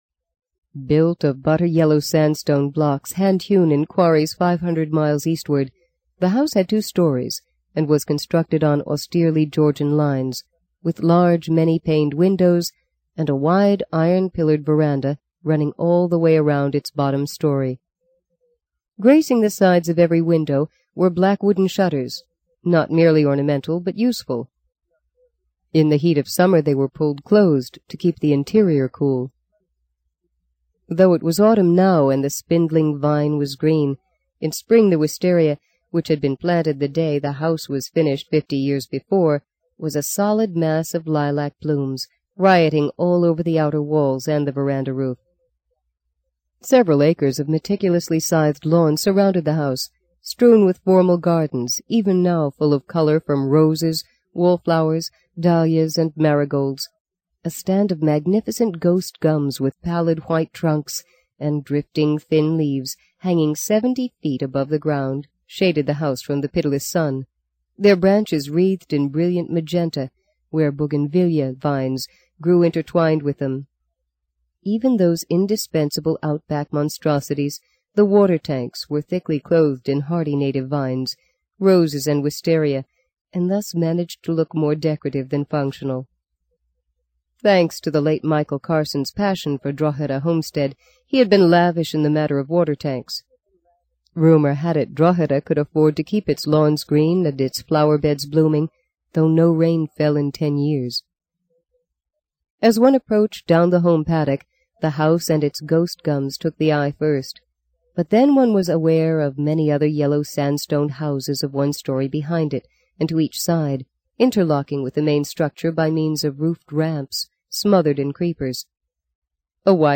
在线英语听力室【荆棘鸟】第三章 02的听力文件下载,荆棘鸟—双语有声读物—听力教程—英语听力—在线英语听力室